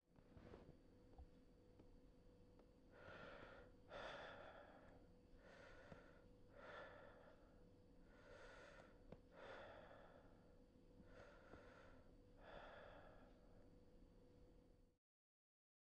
气喘吁吁
描述：视线和呼吸困难/气喘吁吁
标签： 喘息 呼气 吸气 呼气 空气 气喘吁吁 OWI 呼吸 呼吸 呼吸
声道立体声